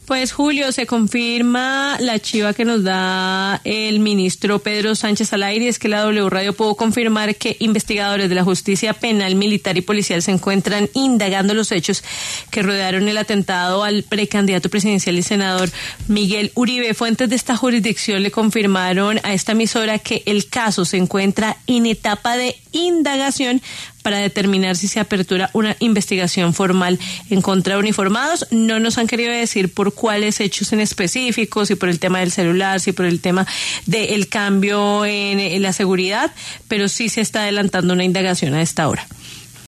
El ministro de Defensa, Pedro Sánchez, informó en los micrófonos de La W sobre esta indagación.